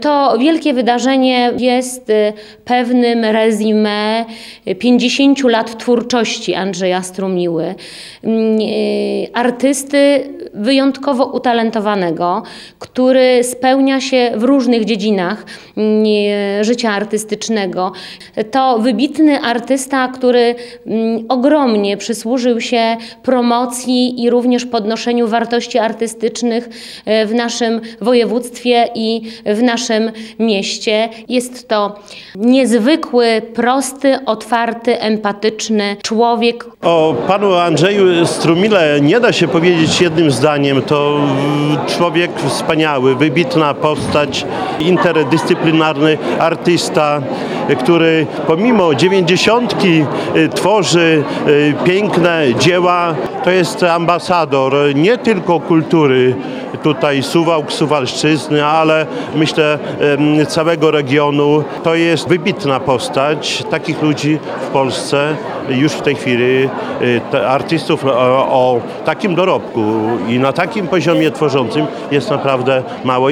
Benefis Andrzeja Strumiłły odbył się w sobotę (21.10) w Suwalskim Ośrodku Kultury.